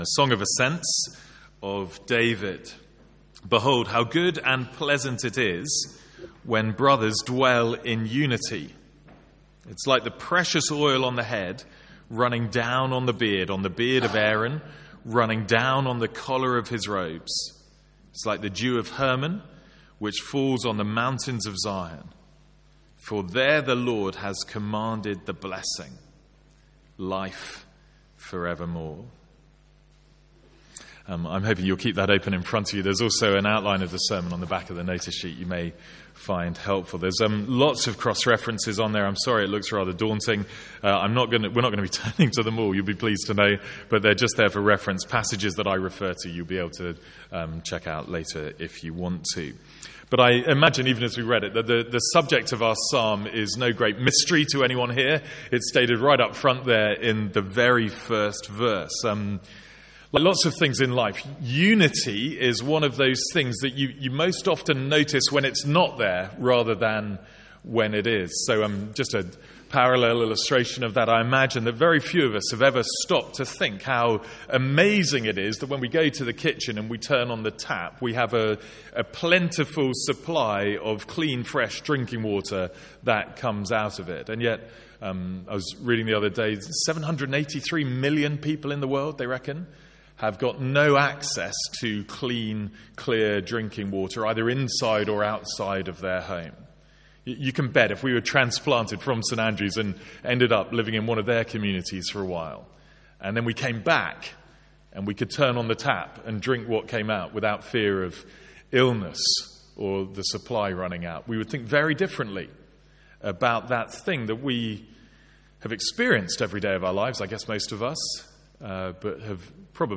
Sermons | St Andrews Free Church
From our Sunday morning series in the Psalms.